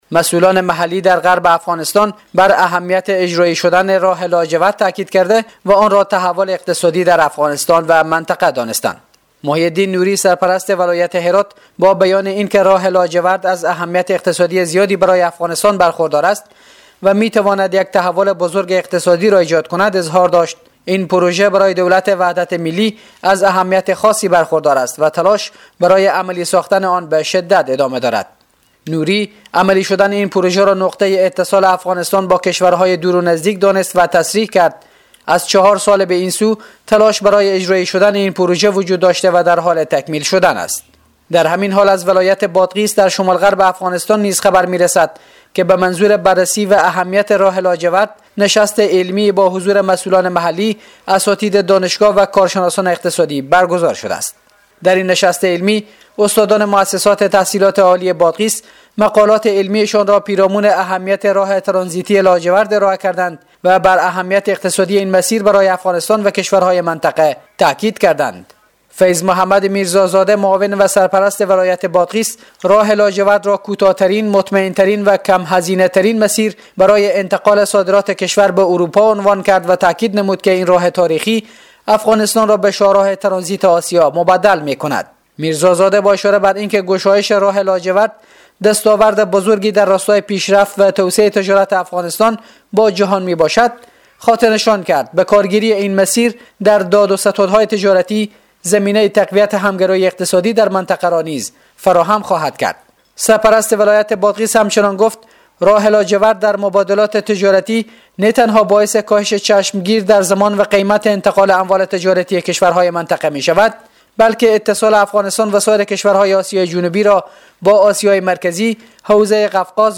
جزئیات بیشتر در گزارش خبرنگار رادیو دری: کلیدواژه افغانستان اشرف غنی هرات خبر خبر افغانس